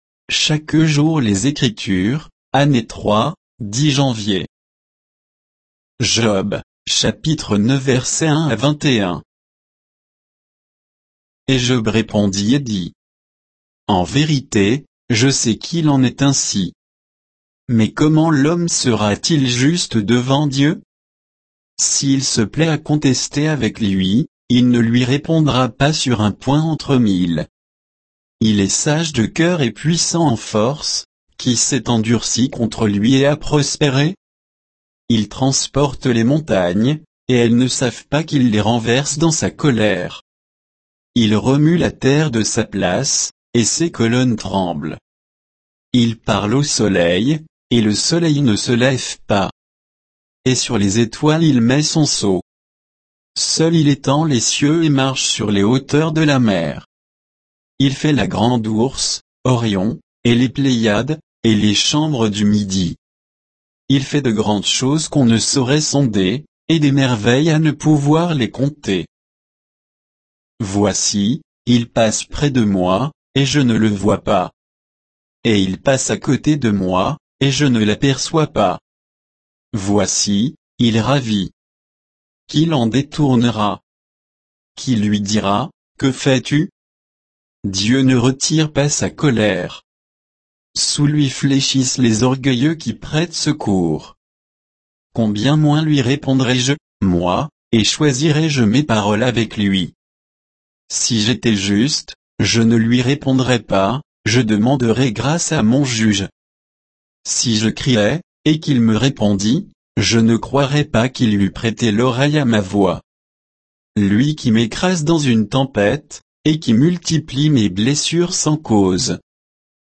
Méditation quoditienne de Chaque jour les Écritures sur Job 9, 1 à 21